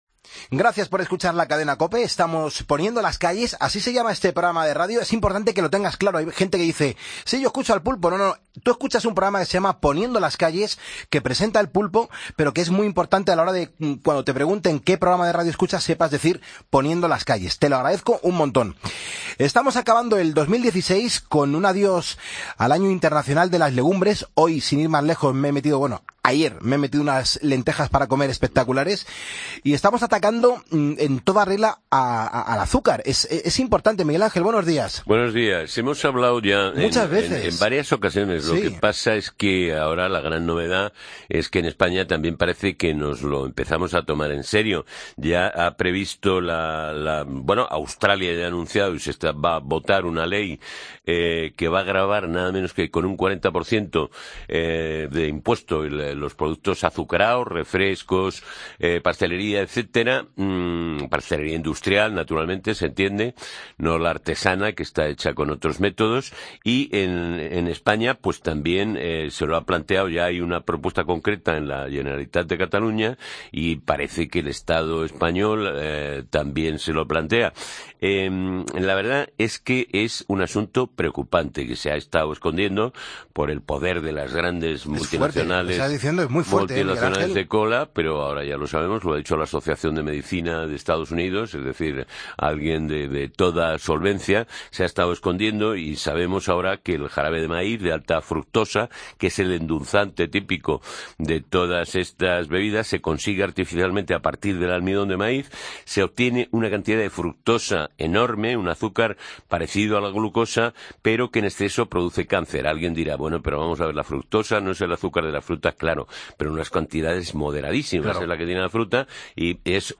experto en nutrición